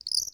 UOP1_Project / Assets / Audio / SFX / Characters / Voices / PhoenixChick / PhoenixChick_01.wav